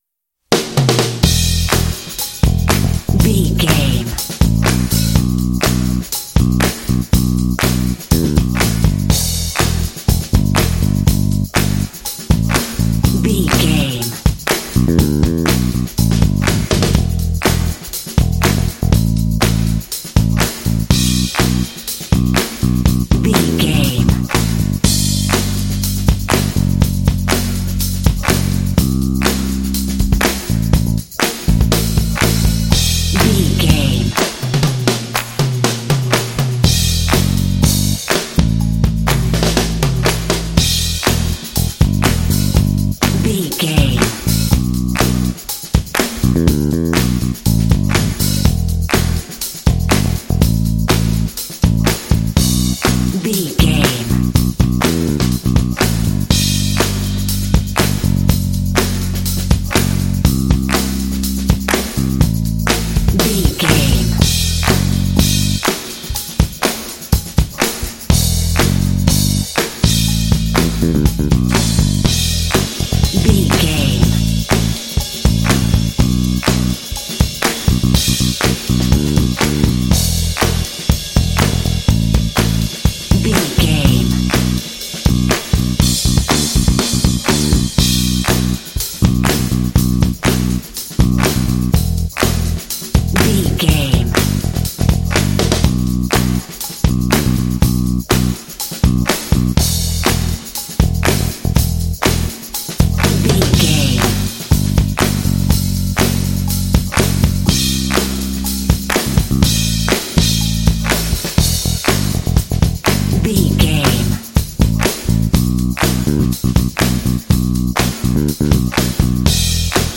Uplifting
Aeolian/Minor
funky
groovy
driving
energetic
lively
bass guitar
drums